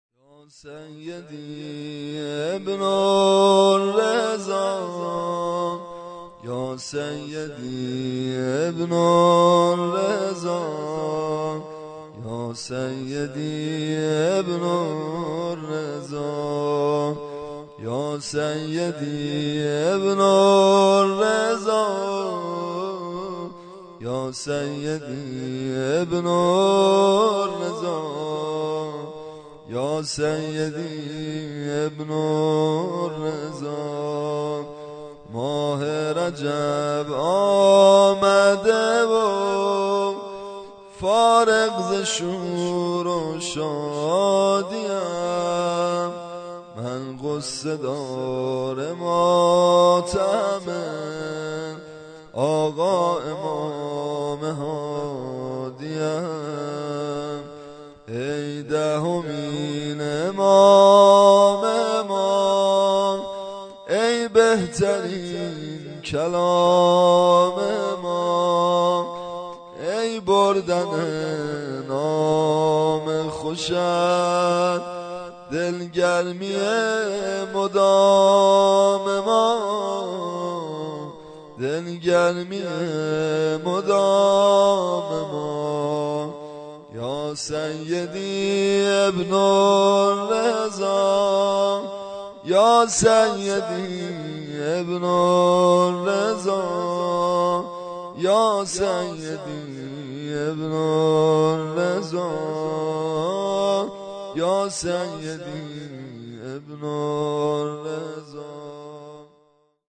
نوحه‌ي واحد شهادت امام هادی(ع) به همراه سبك-ماه رجب آمده و ، فارغ ز شور و شادی‌ام